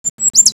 BIRD1.WAV